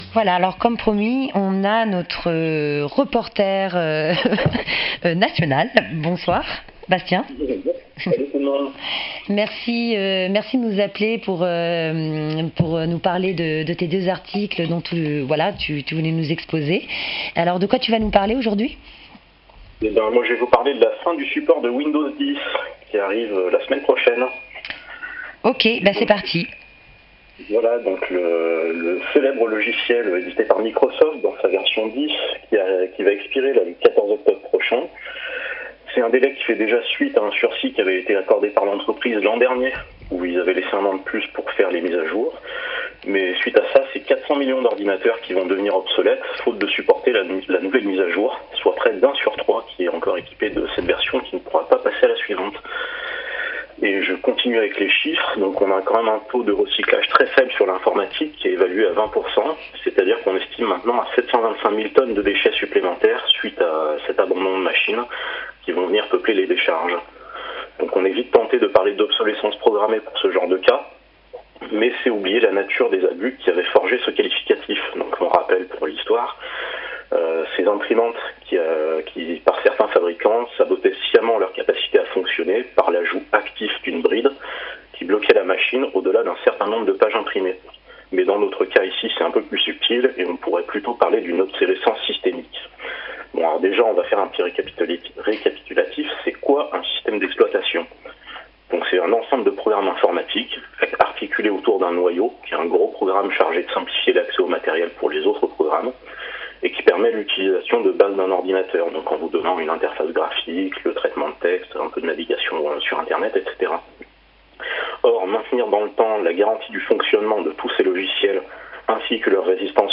Infos du 07/10/2025 sur Radio Zinzine